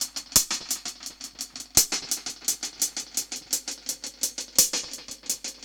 Index of /musicradar/dub-drums-samples/85bpm
Db_DrumsB_EchoHats_85-03.wav